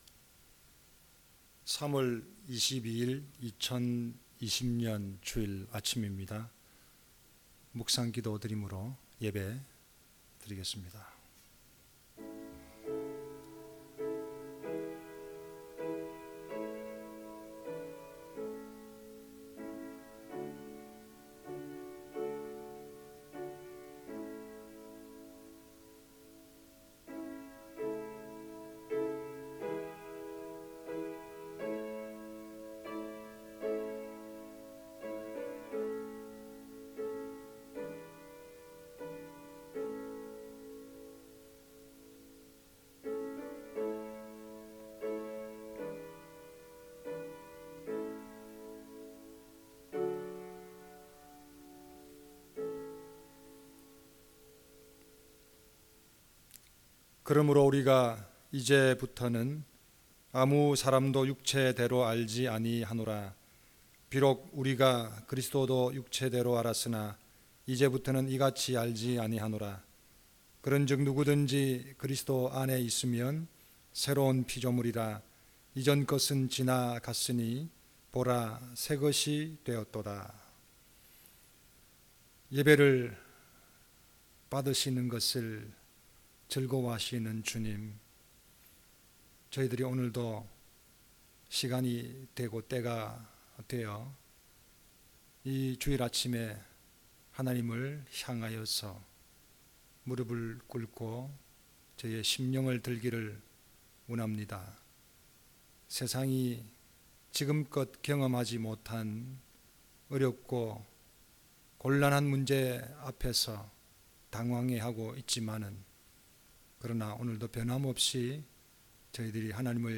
주일 설교